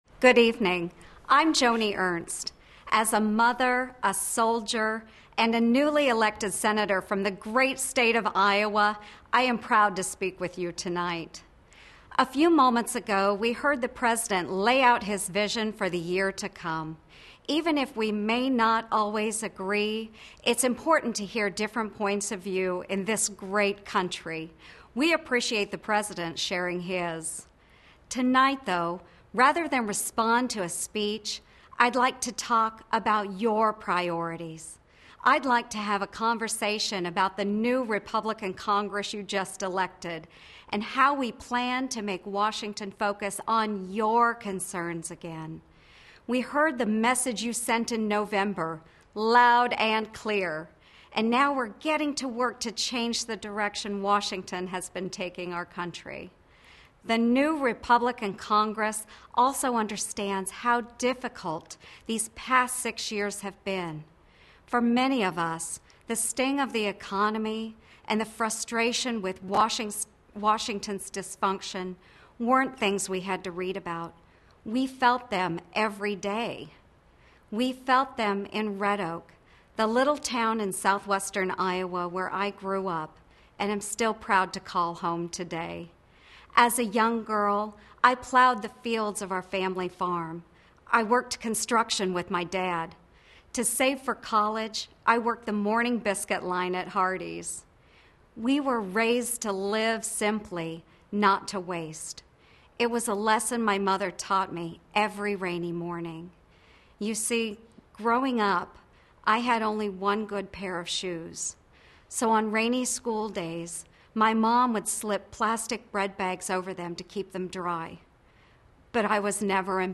Full text of the GOP response to President Obama's State of the Union address as prepared for delivery and given by Sen. Joni Ernst (R-Iowa).